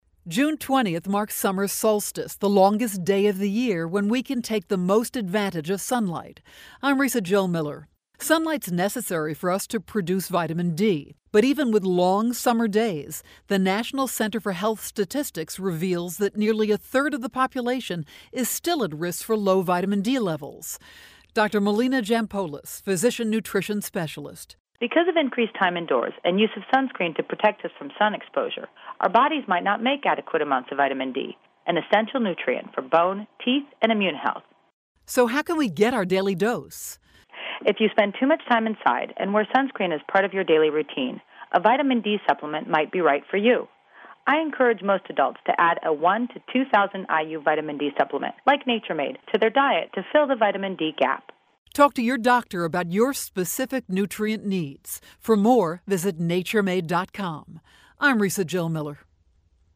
June 14, 2012Posted in: Audio News Release